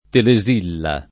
[ tele @& lla ]